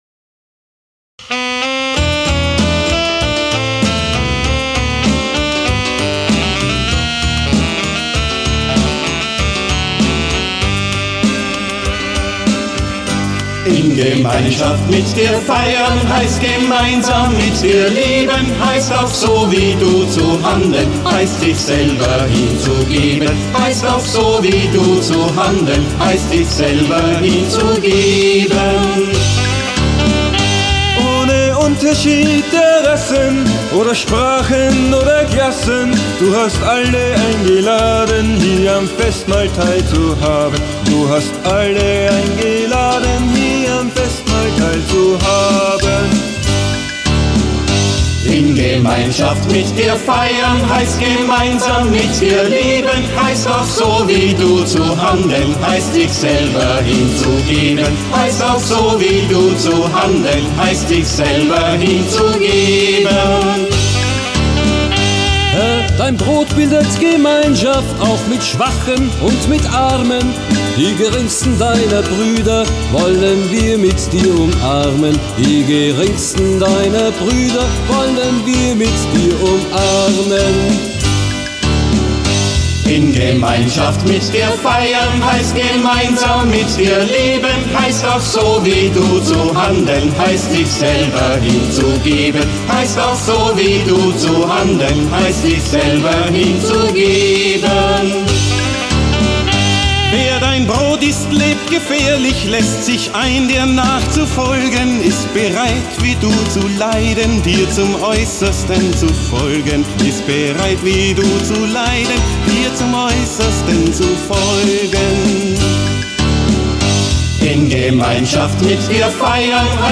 13 Lieder brasilianischer Basisgemeinden wurden übersetzt